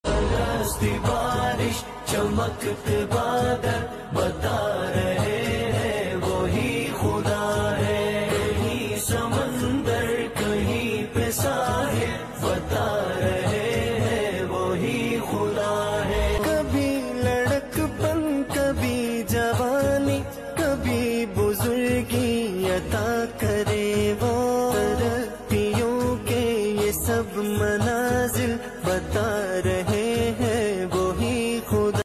Flood and rain in Multan sound effects free download